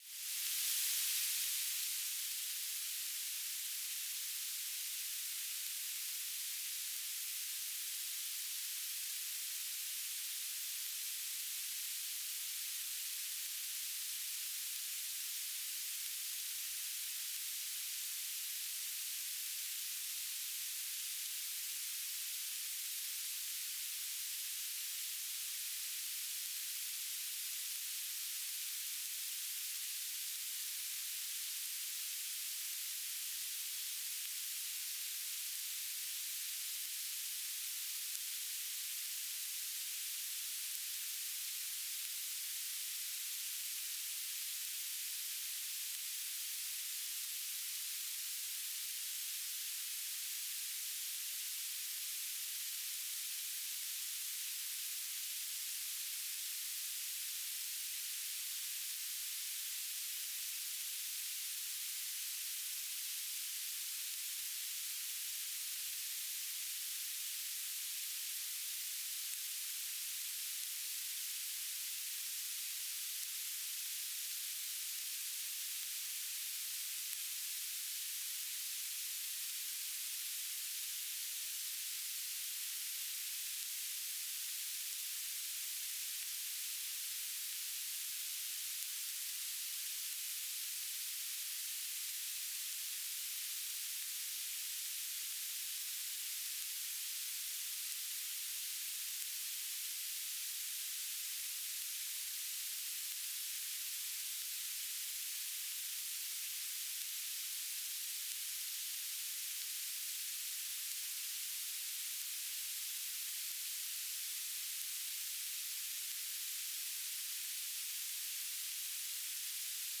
"transmitter_description": "Mode U - BPSK1k2 - Beacon",
"transmitter_mode": "BPSK",